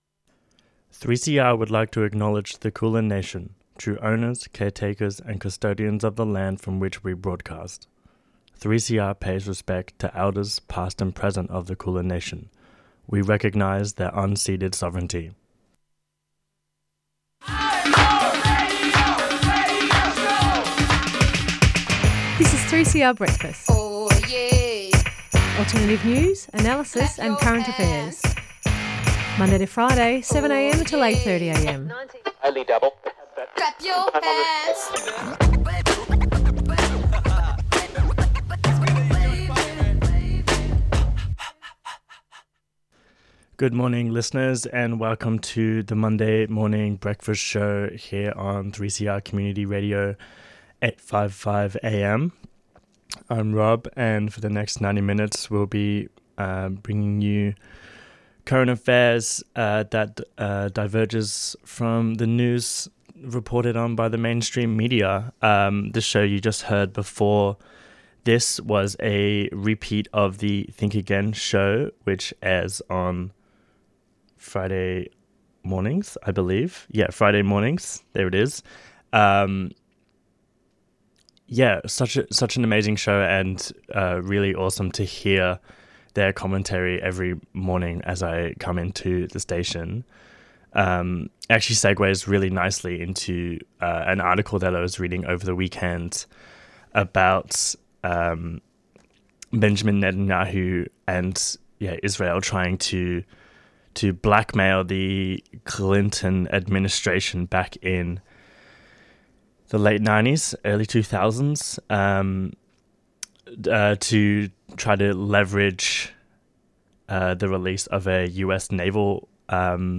Women's Liberation in 70s Naarm | March 4 Forests Rally Speeches | Colonisation Corrupts Land Care |